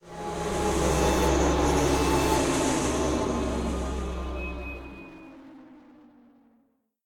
Horror3.ogg